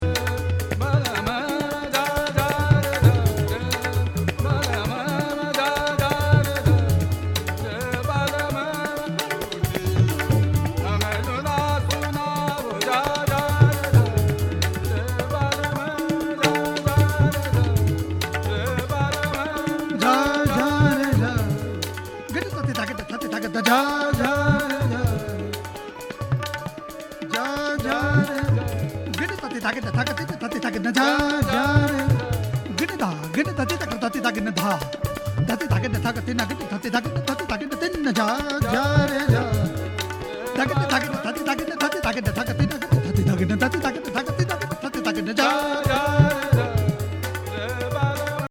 recitation, tabla
western drums
vocal
sitar , harmonium